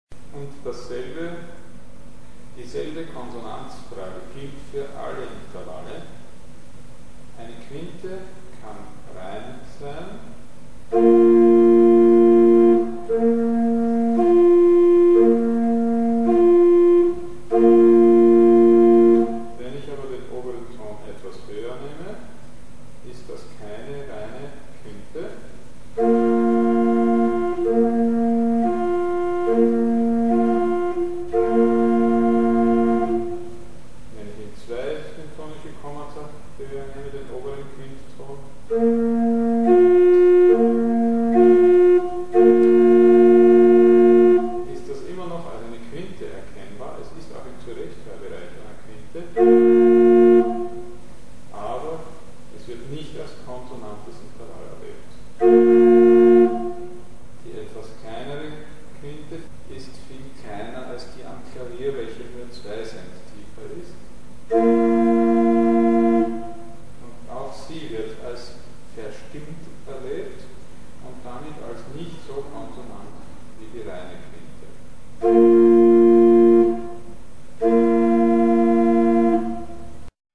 Und selbst wenn man von den „terzverwandten“ kleineren Tasten ausgeht, gibt es wieder zwölf völlig gleich zu greifende Tonarten.
Zu Mikrobuch -  Quinten verschiedene.wma